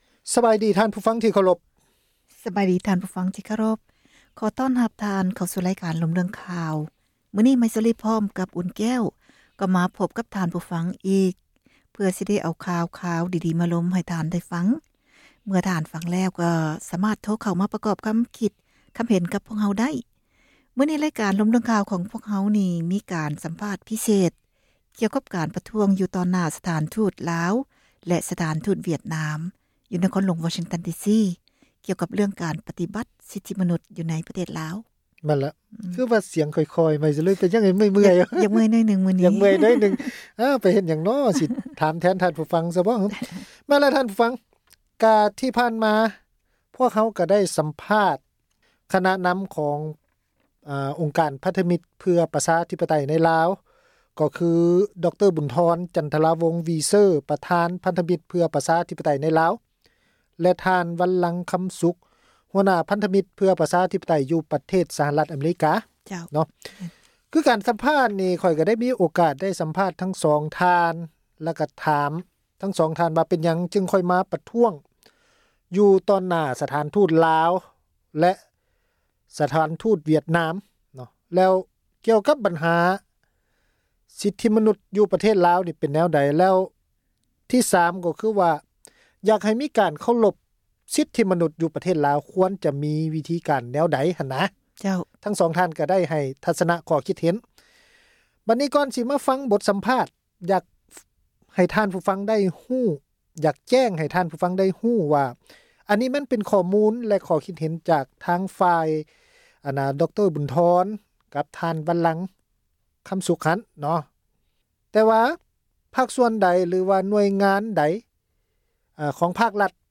ການສຳພາດ ຄນະນຳ ພັນທະມິດ ເພື່ອ ປະຊາທິປະໄຕ ໃນ ລາວ ຄື